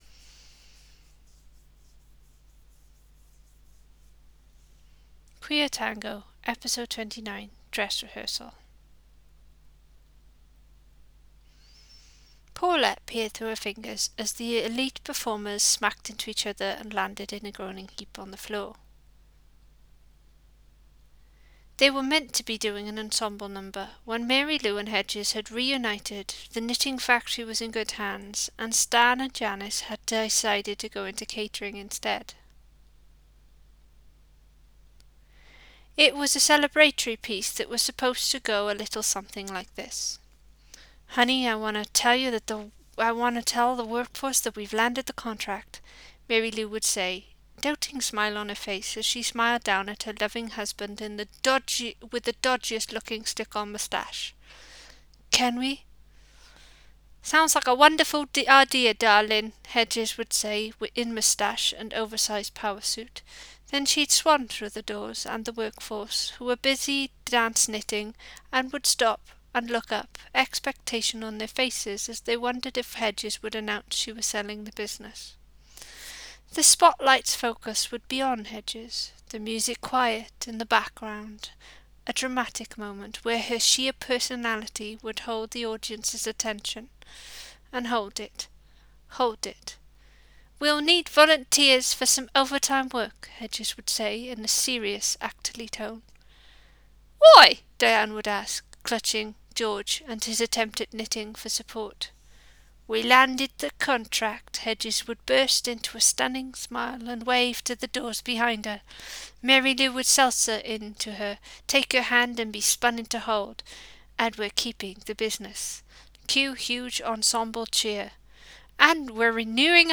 Please excuse bloopers, typos and heavy breathing!